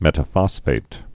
(mĕtə-fŏsfāt)